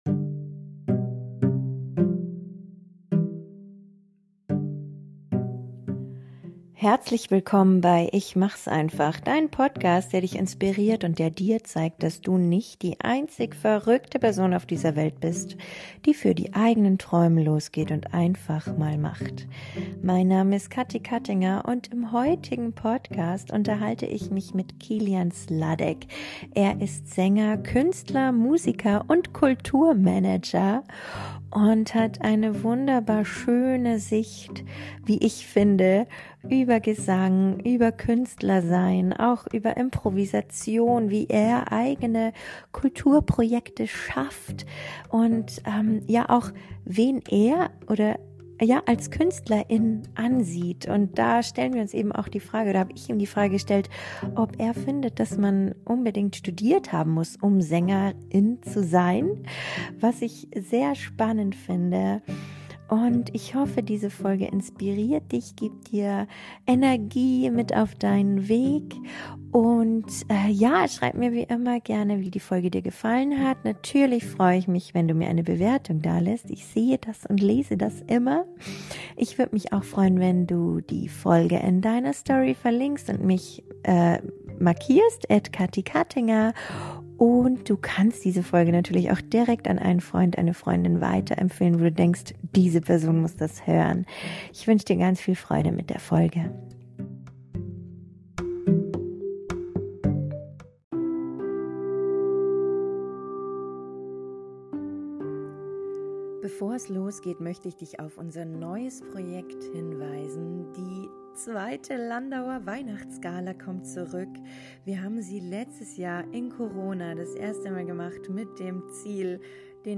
39) Muss man studiert haben, um Sänger*in zu sein? (Interview